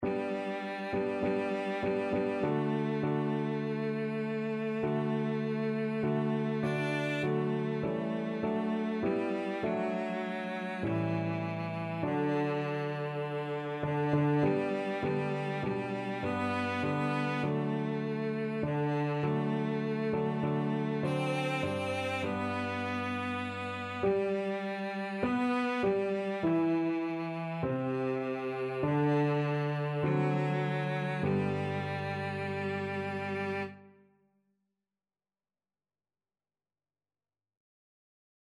Cello
G major (Sounding Pitch) (View more G major Music for Cello )
2/2 (View more 2/2 Music)
Classical (View more Classical Cello Music)